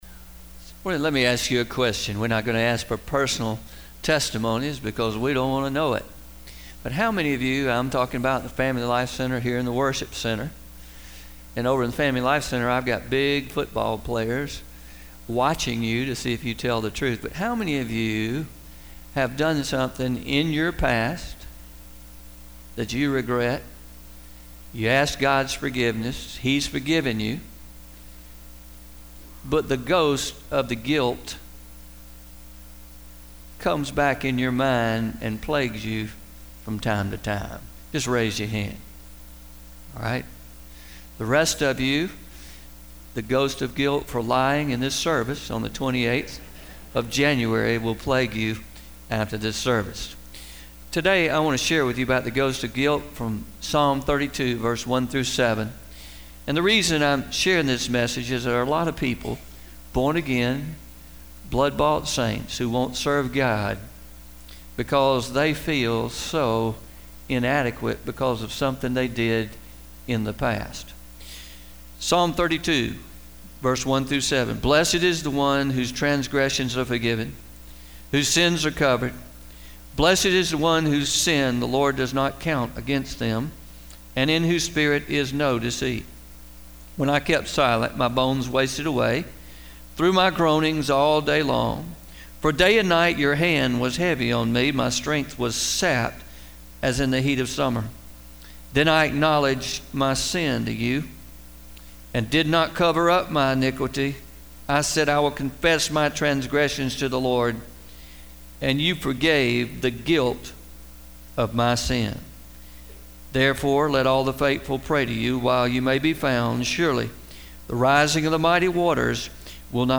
01-28-18am Sermon – The Ghost of Guilt